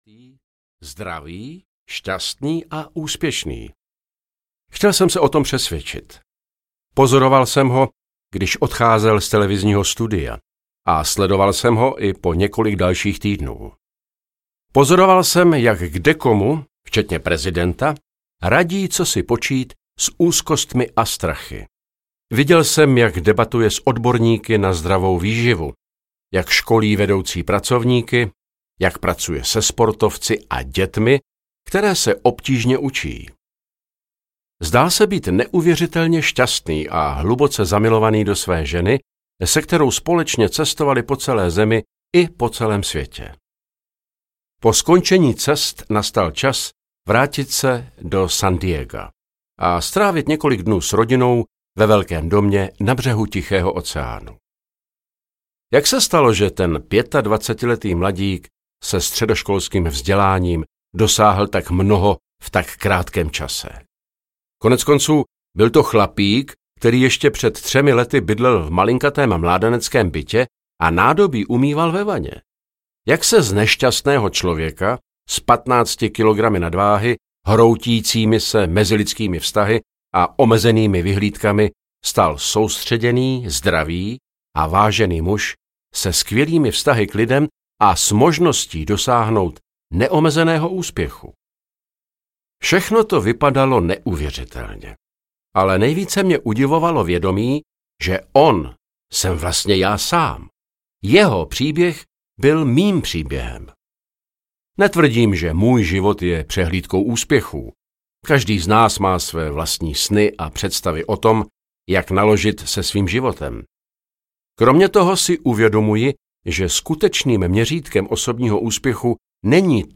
Nekonečná síla audiokniha
Ukázka z knihy